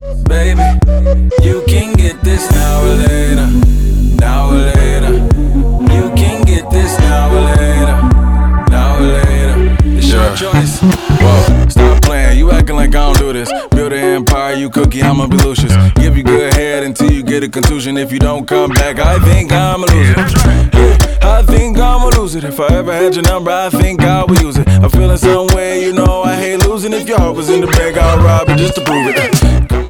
• Hip-Hop